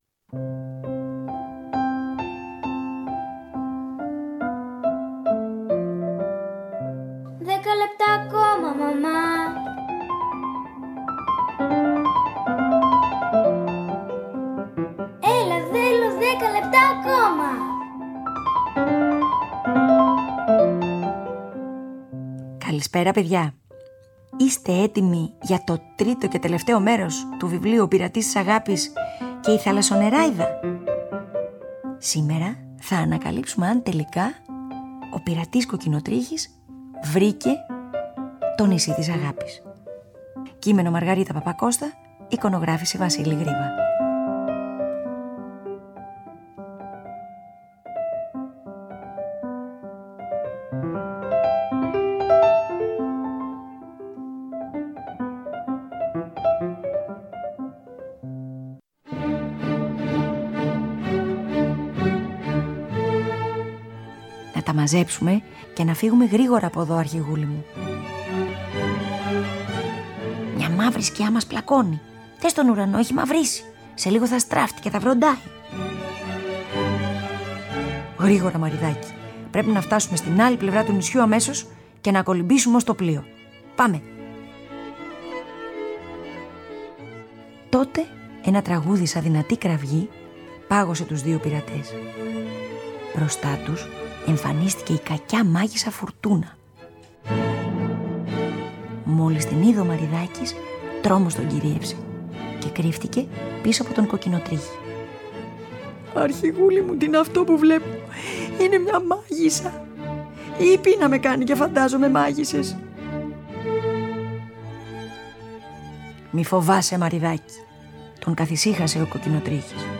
Wolfgang Amadeus Mozart